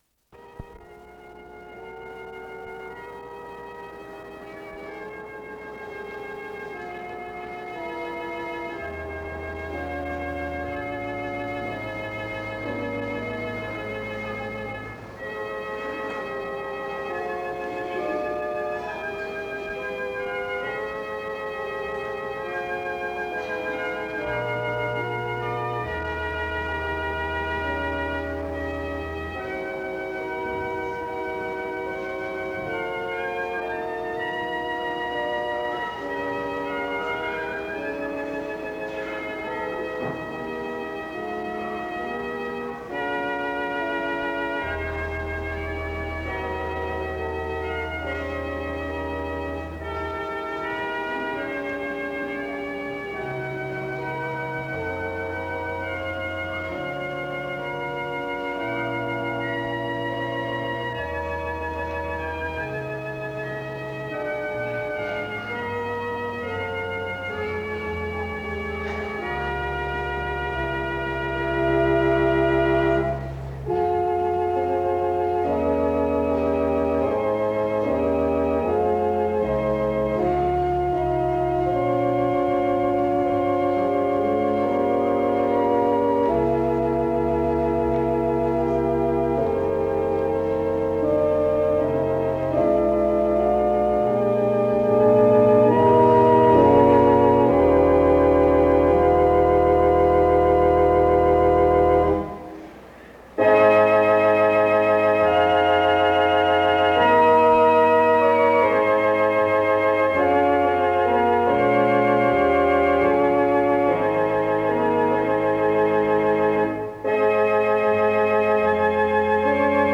Download .mp3 Description After some songs and the opening prayer (start-5:05), Isaiah 40:18-31 and 2 Corinthians 5:10-21 are read to the graduates (5:06-12:03).
SEBTS Chapel and Special Event Recordings SEBTS Chapel and Special Event Recordings